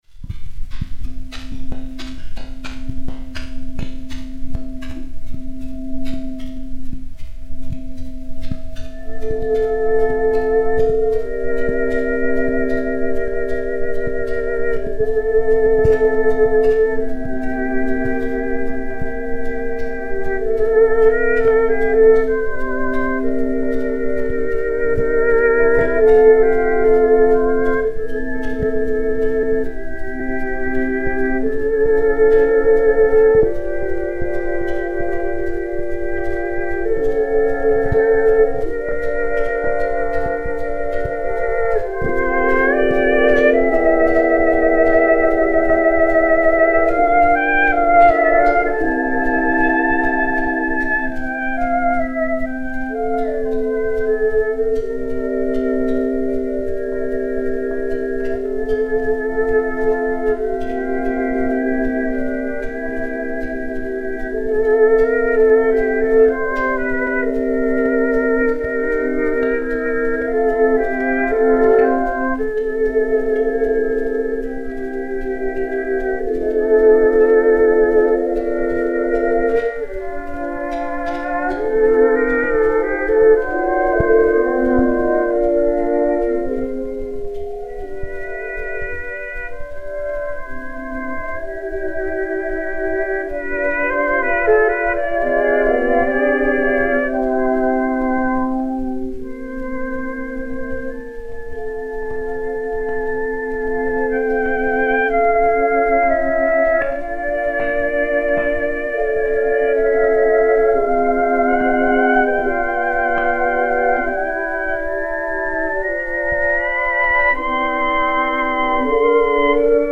Quatuor à cordes
Disque Pour Gramophone 30727, mat. 15523u, enr. vers 1910